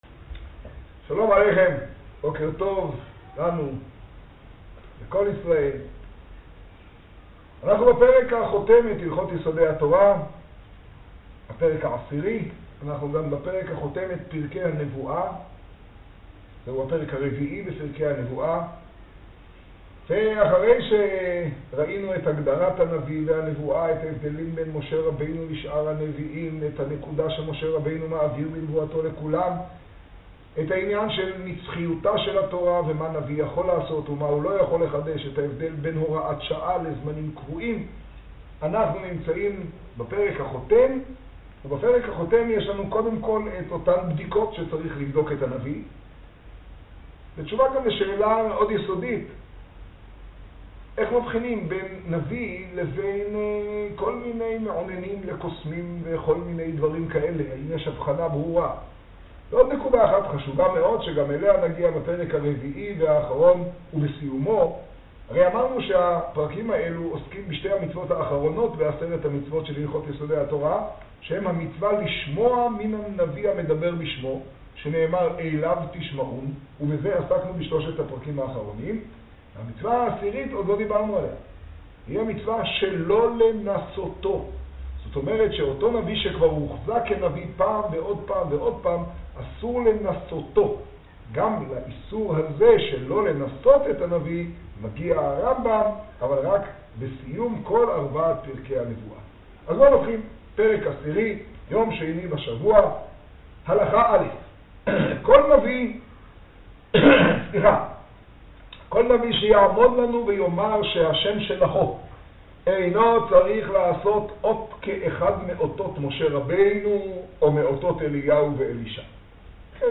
השיעור במגדל, יד טבת תשעה.